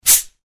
Звуки газировки
Звук відкриття газировки: